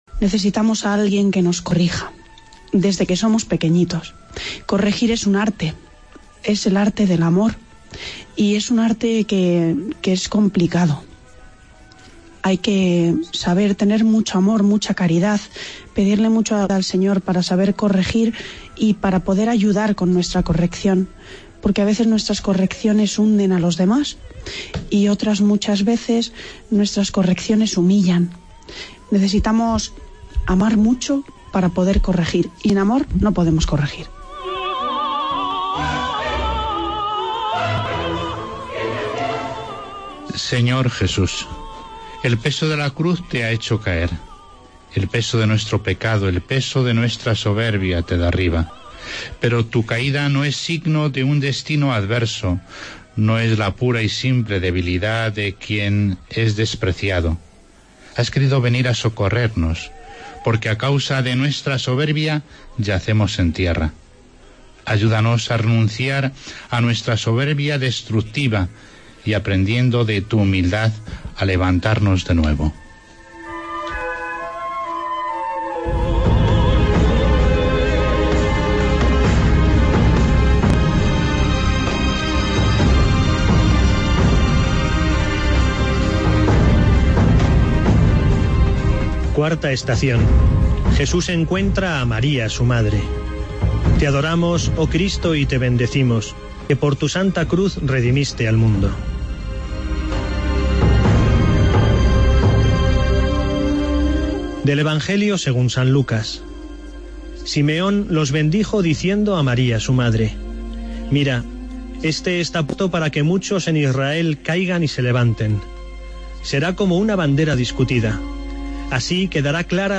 AUDIO: Vía Crucis de Penitencía desde Avila